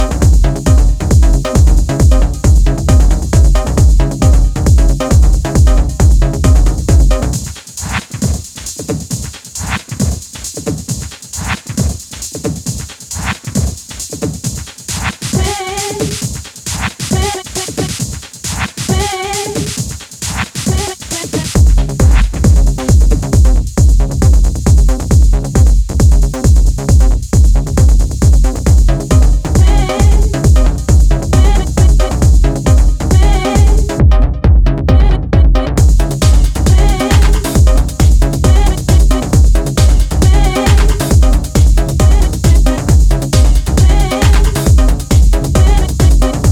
Two raw, distinctive, ultra-dry dancefloor slammers